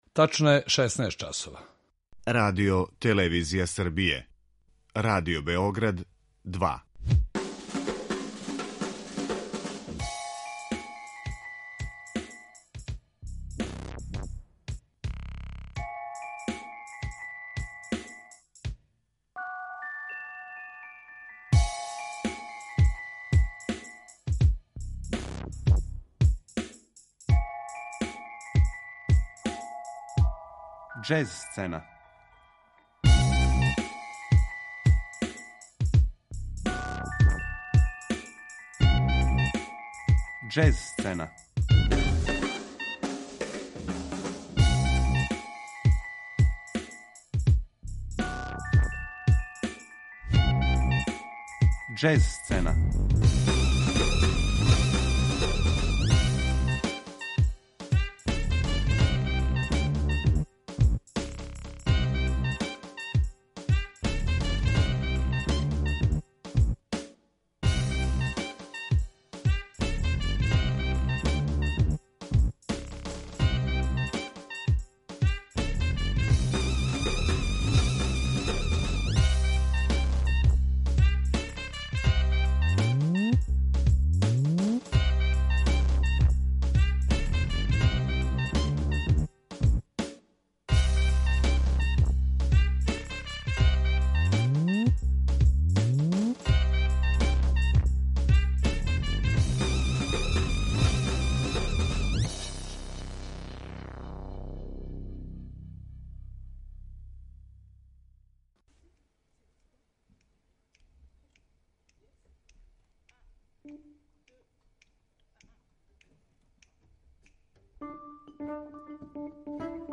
Приказ звучно илуструјемо актуелним снимцима учесника.